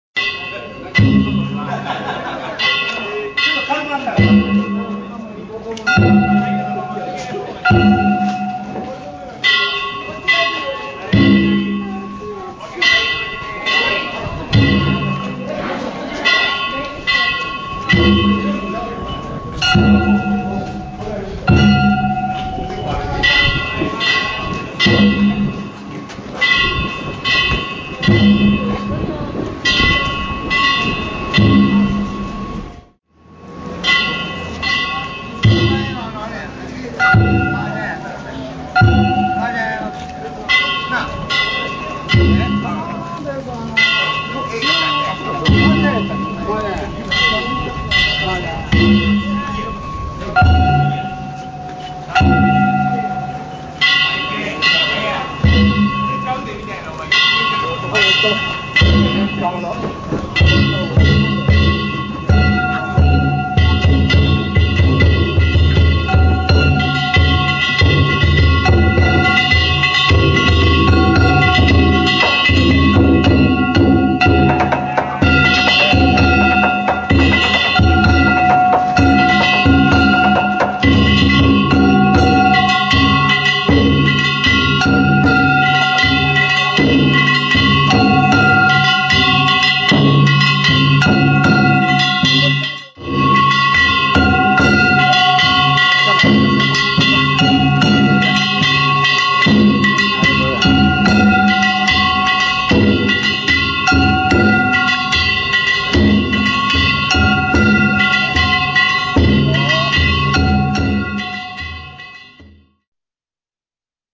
平成２９年７月１６日、鶴見区の鶴見西之町地車夏祭り曳行を見に行ってきました。
鶴見神社近くまで来ると鉦太鼓の音が聞こえてきます(＾◇＾)
口上と手打ちです。
ゆっくりとしたお囃子での村中曳行です。
道が広くなり、お囃子が速くなりました。